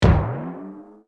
[影视音效][舞台表演魔术的音效][剪辑素材][音频素材下载]-8M资料网